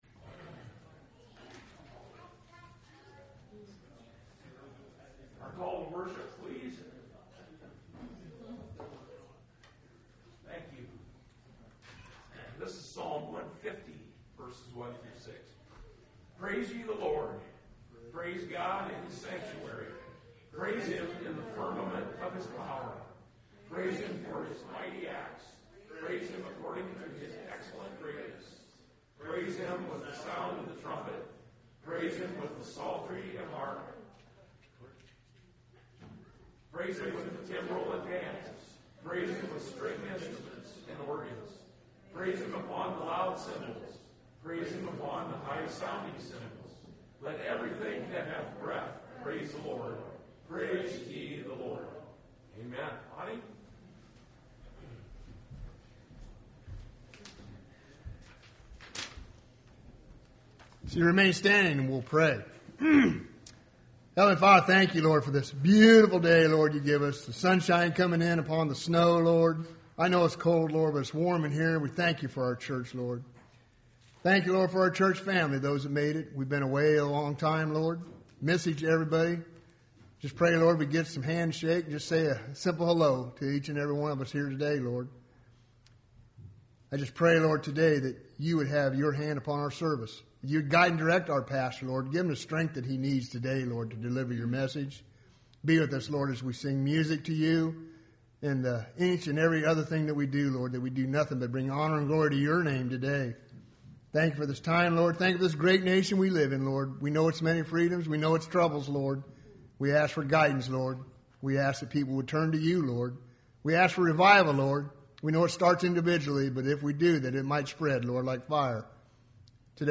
Service Type: Sunday Morning Service Topics: Christian Living , Encouragement , New Year Challenge « The Lost Doctrine of Separation